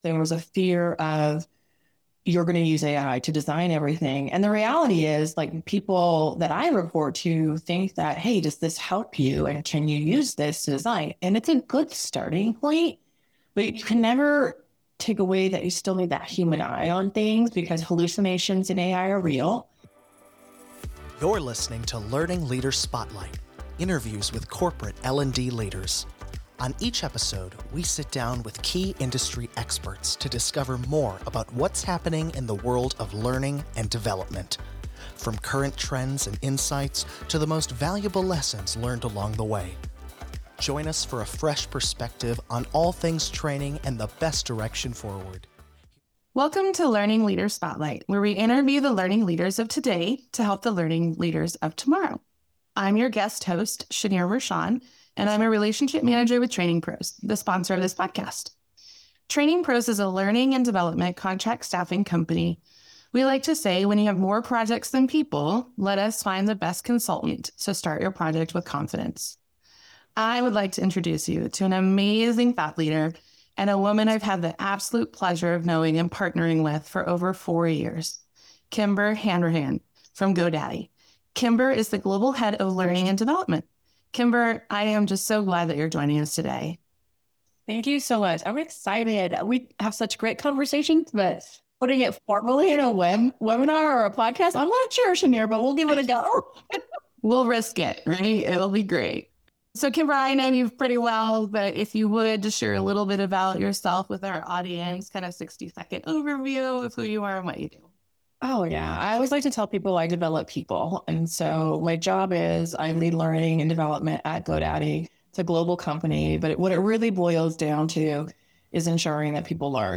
Interviews with corporate L&D leaders